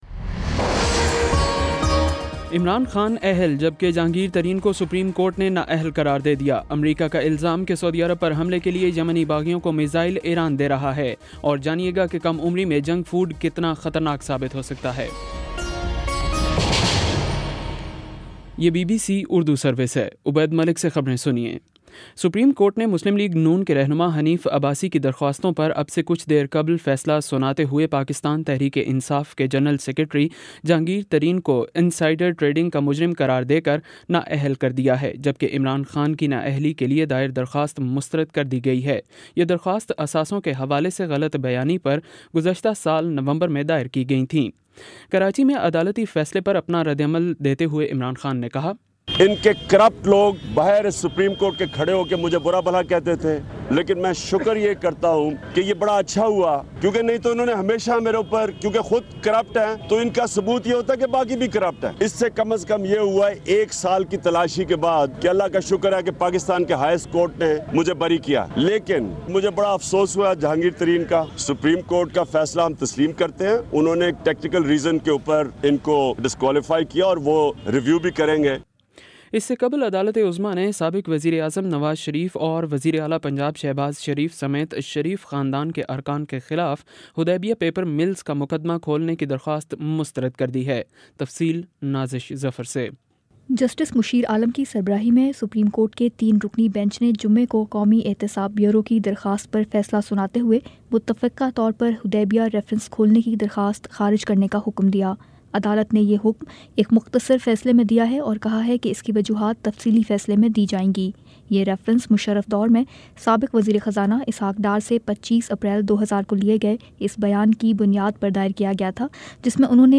دسمبر 15 : شام پانچ بجے کا نیوز بُلیٹن
دس منٹ کا نیوز بُلیٹن روزانہ پاکستانی وقت کے مطابق شام 5 بجے، 6 بجے اور پھر 7 بجے۔